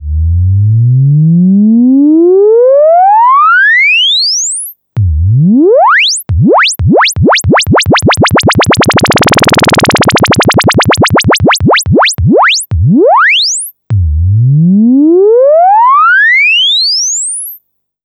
Oscillations 1.wav